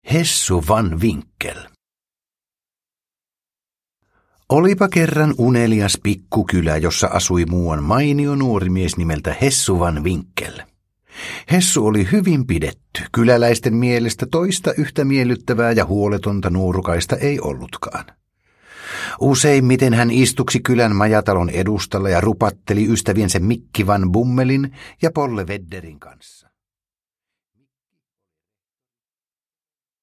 Hessu Van Winkel – Ljudbok – Laddas ner